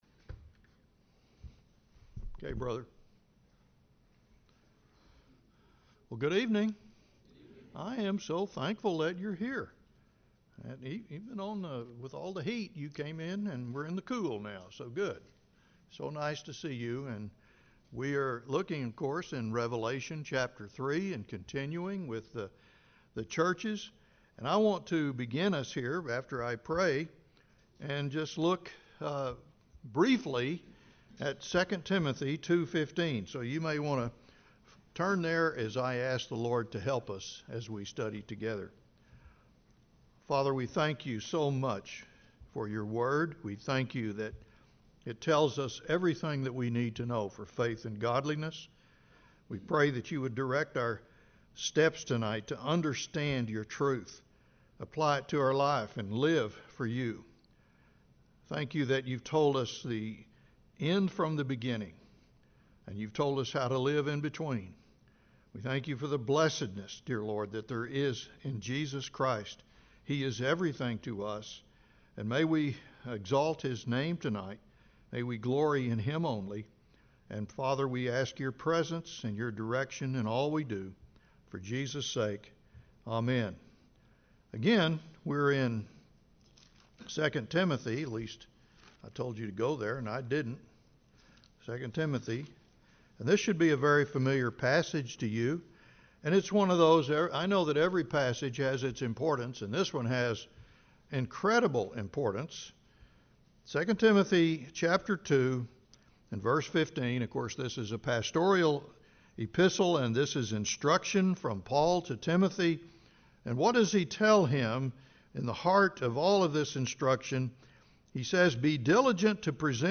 Service Type: Bible Study